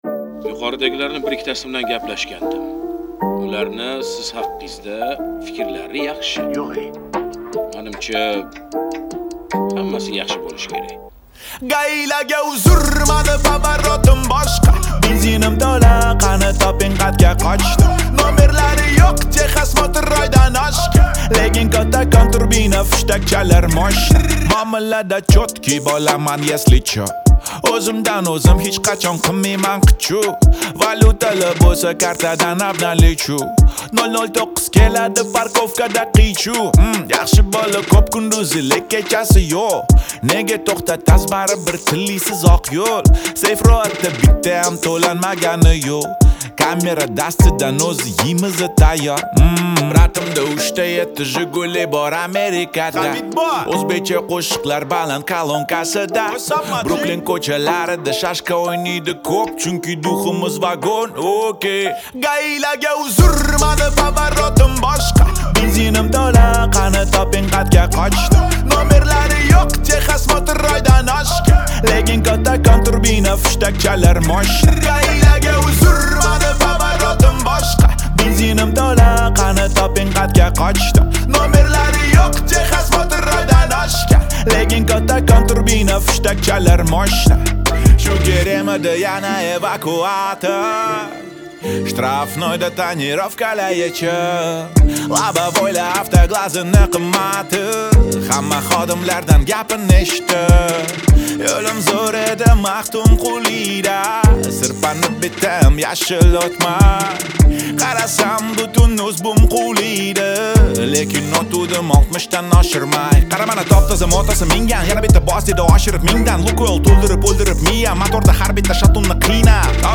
Трек размещён в разделе Узбекская музыка / Рэп и хип-хоп.